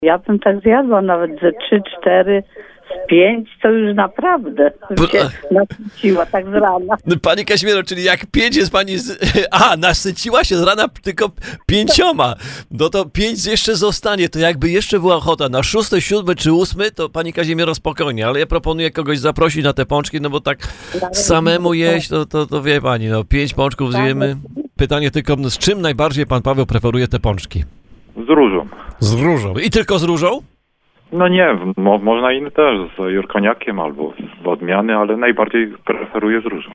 Dlatego też tradycyjnie na antenie RDN Małopolska i RDN Nowy Sącz rozdajemy słodkości. Od samego rana rozdzwoniły się telefony od słuchaczy, którzy przyznają, ze pączki jedzą już na śniadanie.